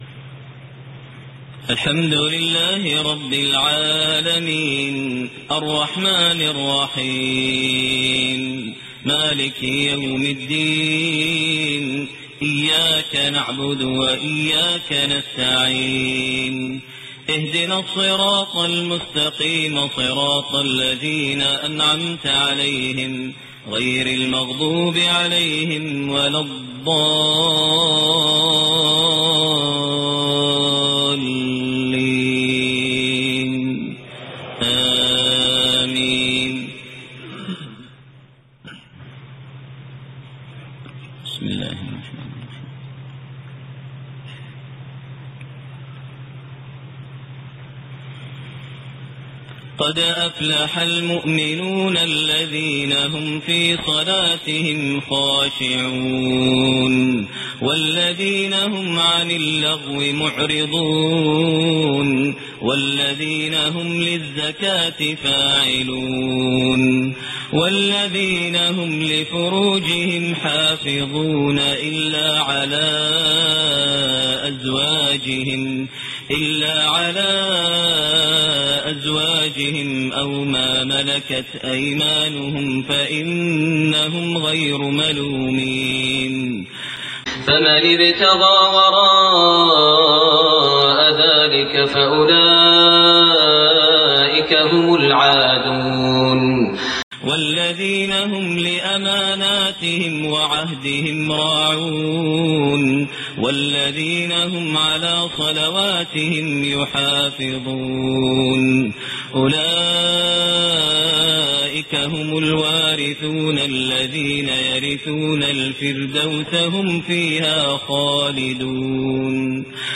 Fajr prayer from Surah Al-Muminoon > 1429 H > Prayers - Maher Almuaiqly Recitations